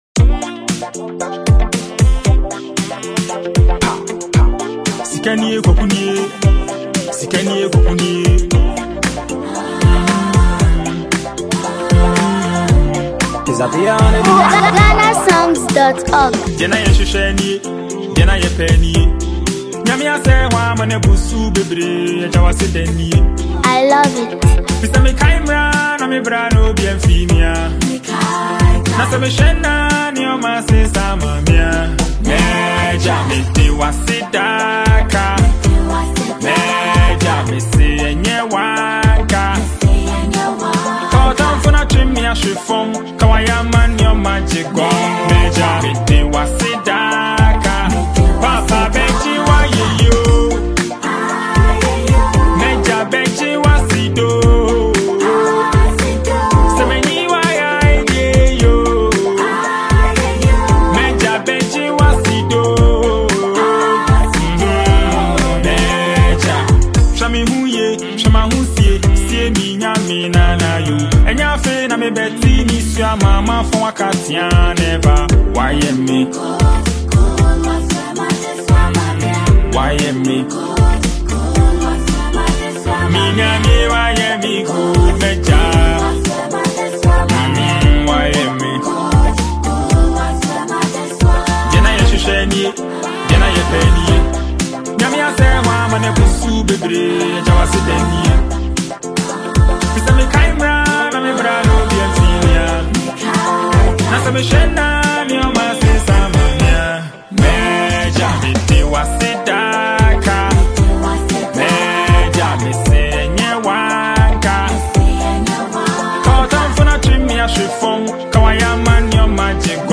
With honest lyrics and a calm yet powerful delivery
smooth production, touching harmonies, and soulful rhythm